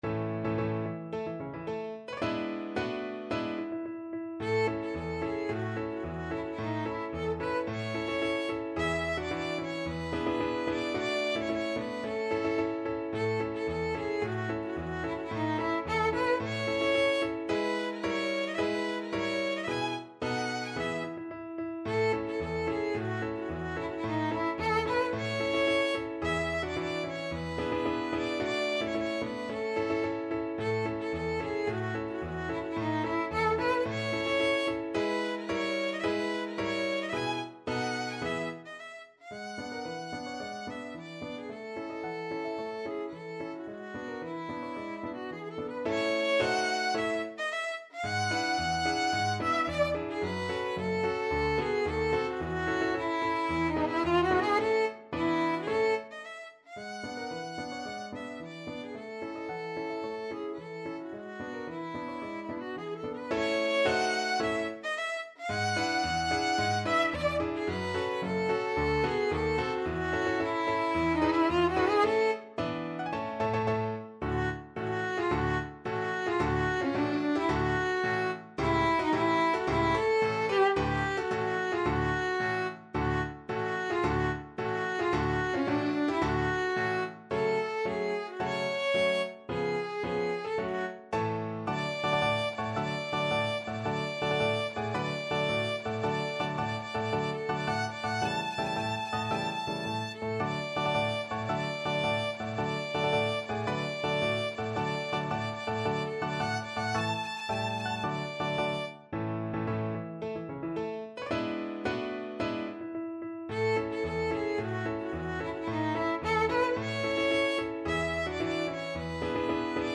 Violin
A major (Sounding Pitch) (View more A major Music for Violin )
March =c.110
2/2 (View more 2/2 Music)
Classical (View more Classical Violin Music)